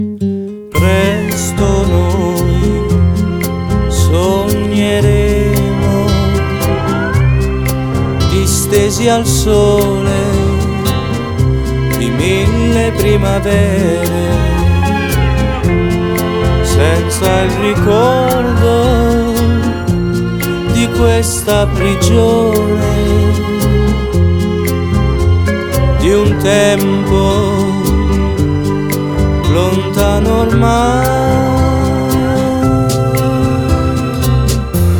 2006-06-09 Жанр: Поп музыка Длительность